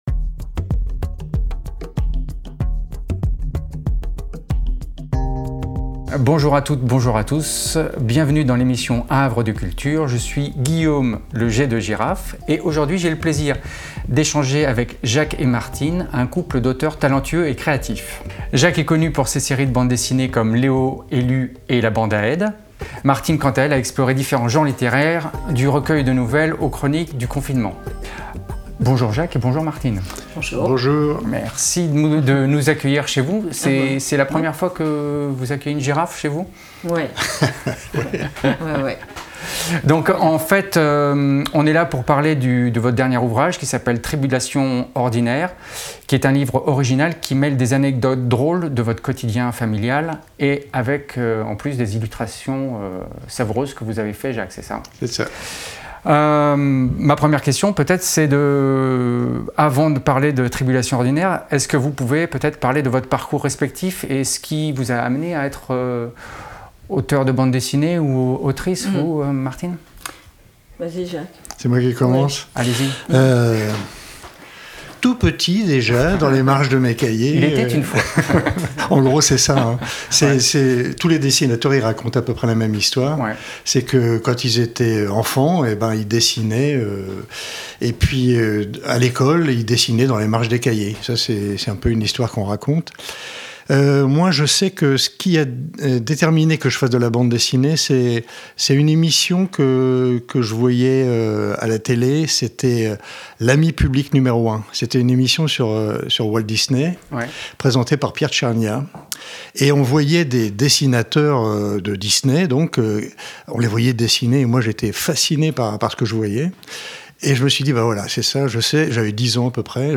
Ce livre atypique mêle anecdotes drôles du quotidien familial et illustrations pleines de vie, offrant un moment de lecture léger et chaleureux. Découvrez les coulisses de cette création et le parcours artistique du duo dans cette interview enrichissante !